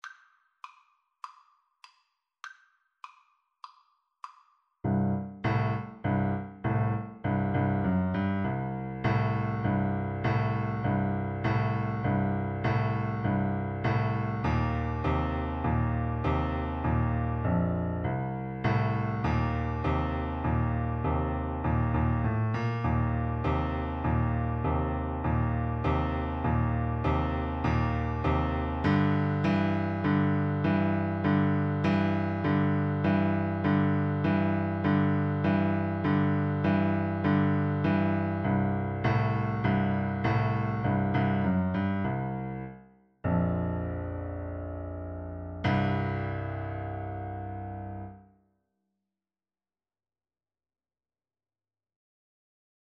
Stomping
Jazz (View more Jazz Tuba Music)